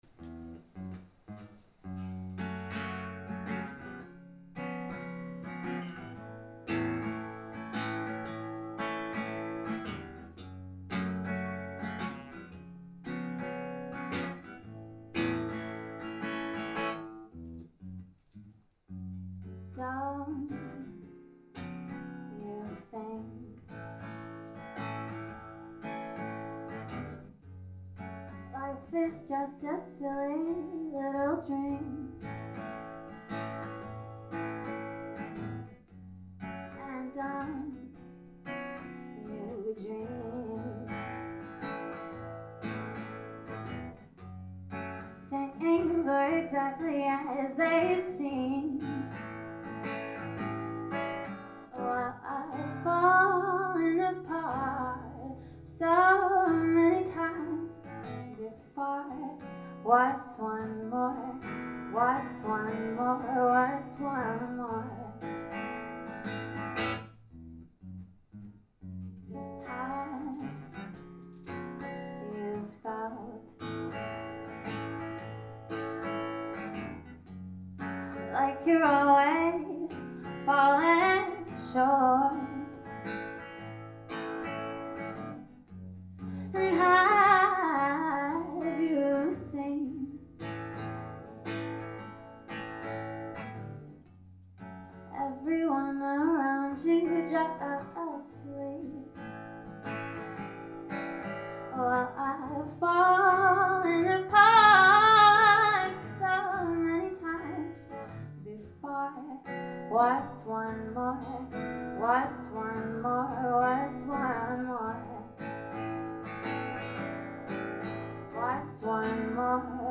Music
An original song